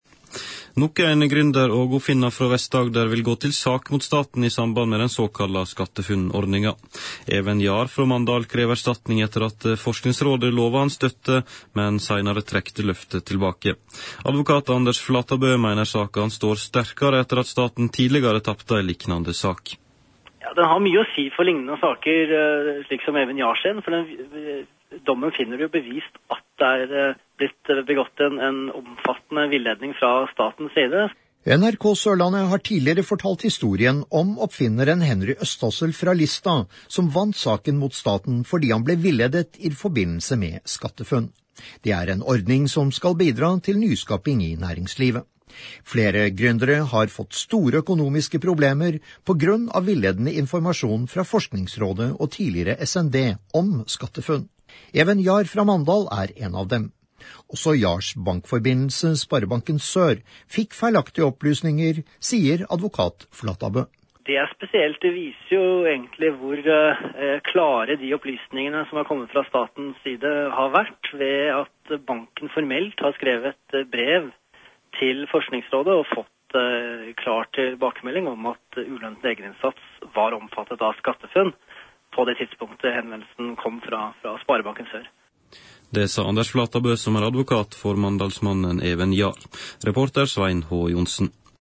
Morgensending med innslag